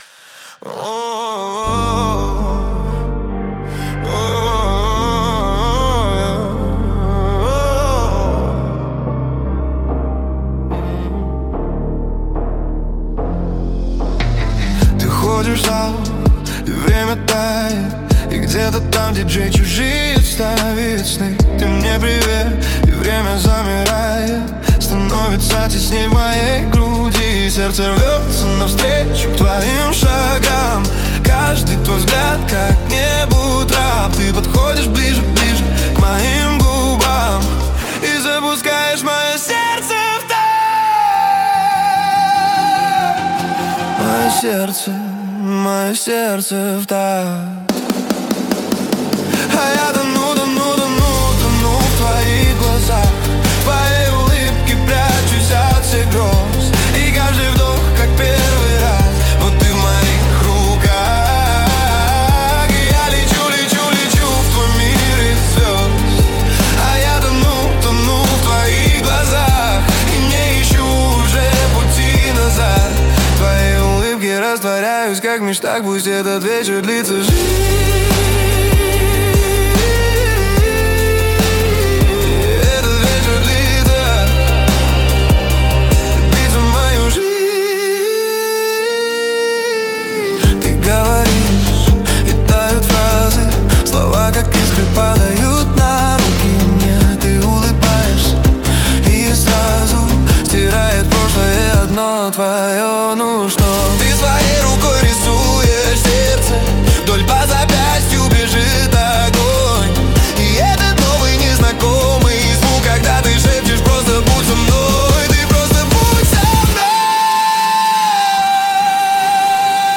techno
Ух, эти ритмы ))) Отлично ))
Красиво и атмосферно!
очень душевно и танцевально получилось!